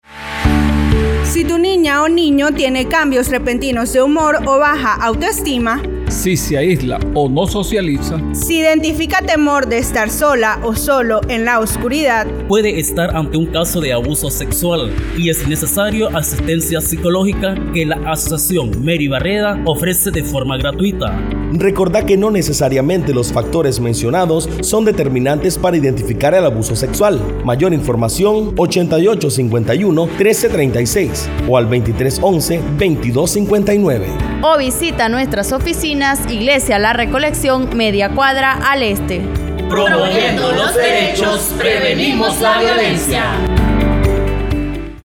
Como parte de la responsabilidad social compartida periodistas, comunicadoras y comunicadores sociales integrados en los procesos de formación que desarrolla la Asociación Mary Barreda sobre el abordaje de las Noticias con Enfoque de Derechos, elaboraron viñetas radiales en prevención de la violencia basada en género, abuso sexual y servicios que ofrece la organización.
Vineta-Prevencion-Abuso-Sexual-1.mp3